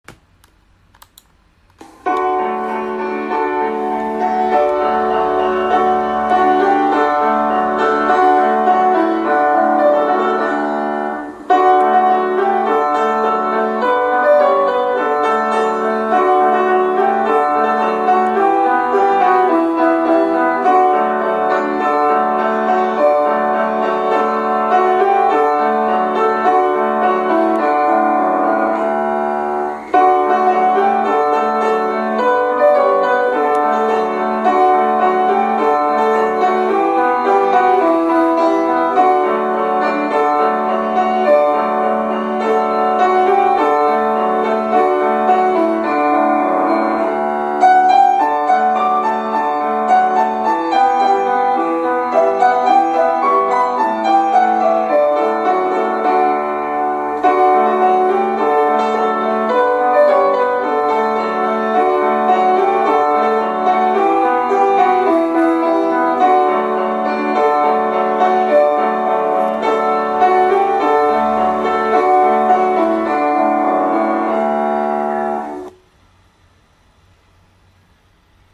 花の木幼稚園の「園歌」を先生がピアノで弾いています。